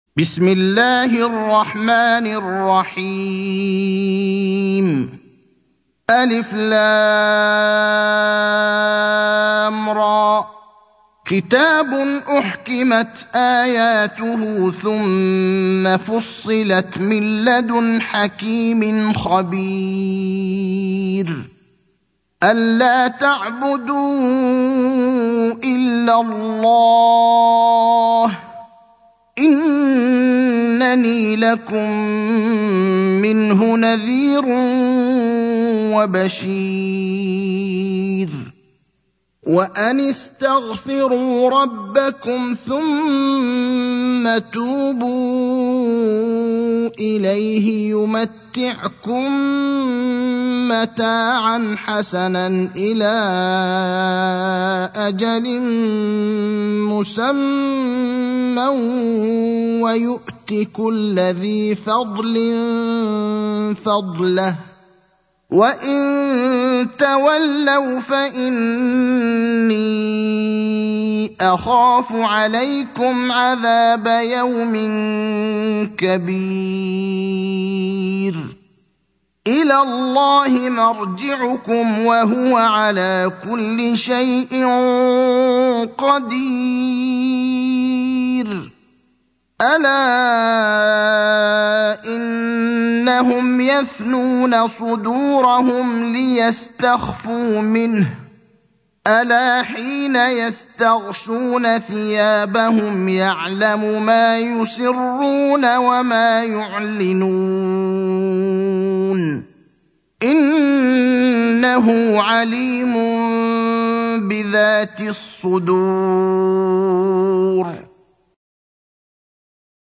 المكان: المسجد النبوي الشيخ: فضيلة الشيخ إبراهيم الأخضر فضيلة الشيخ إبراهيم الأخضر هود (11)